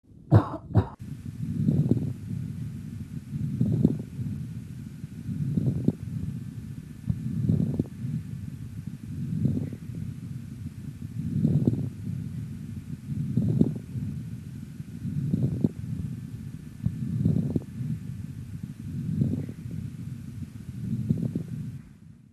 Звук розового шума